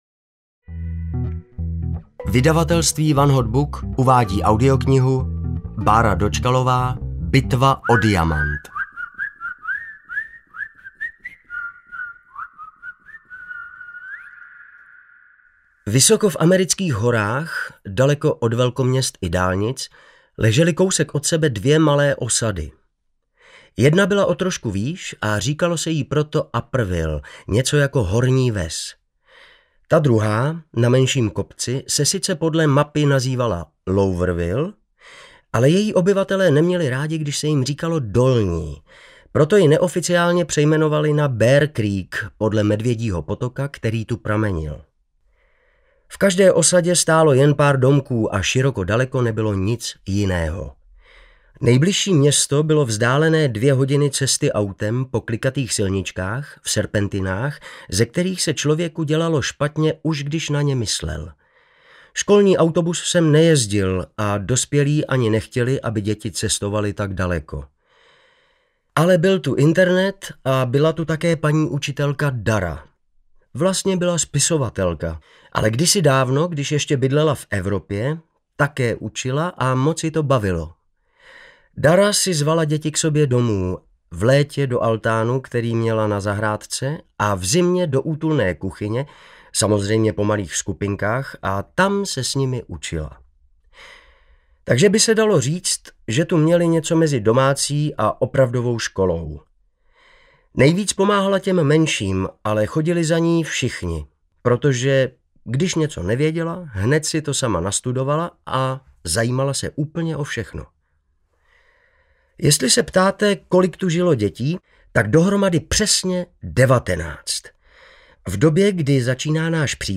Bitva o diamant audiokniha
Ukázka z knihy